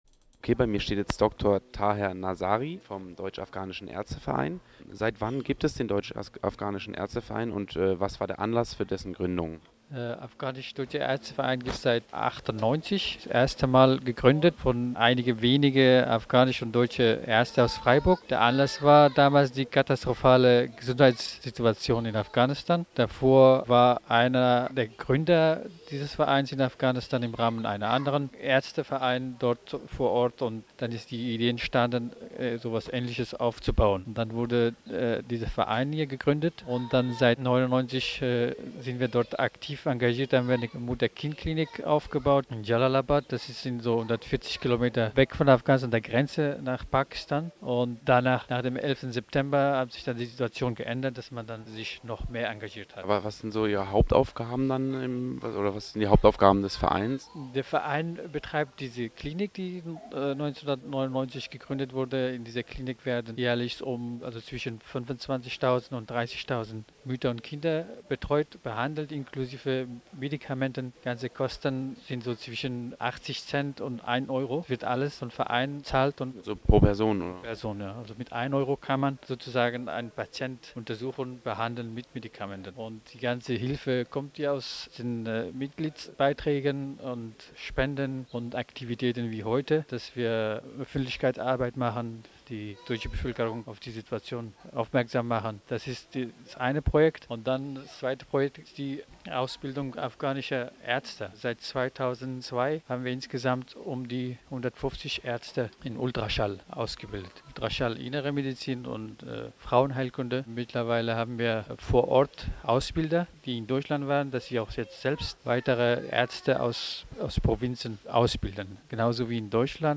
interviewte